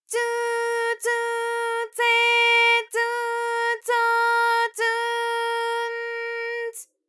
ALYS-DB-001-JPN - First Japanese UTAU vocal library of ALYS.
tsu_tsu_tse_tsu_tso_tsu_n_ts.wav